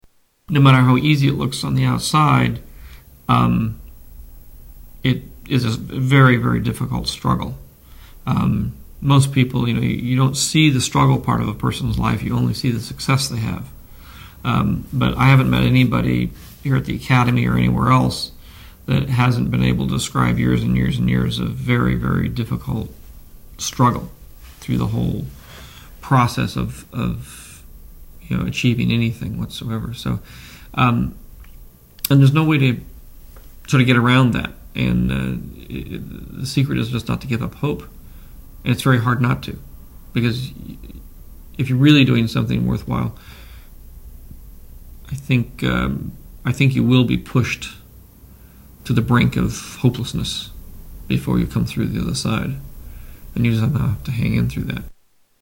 Tags: Media George Lucas audio Interviews George Lucas Star Wars Storywriter